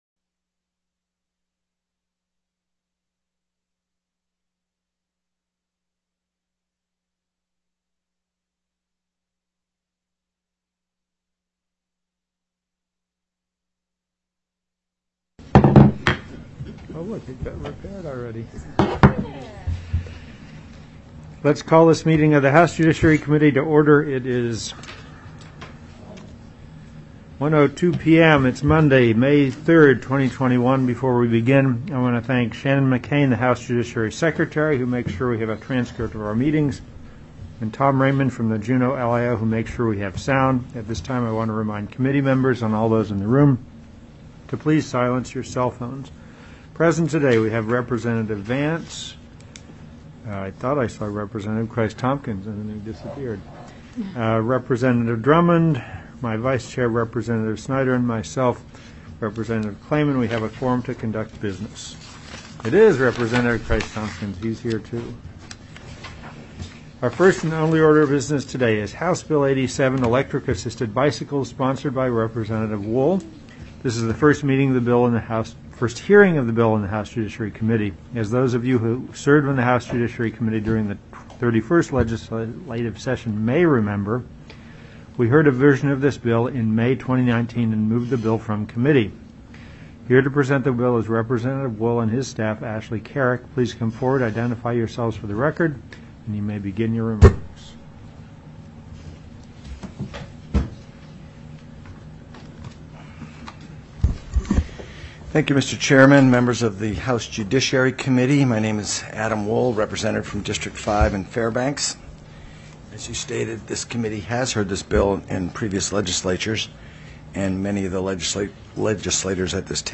TELECONFERENCED
Public Testimony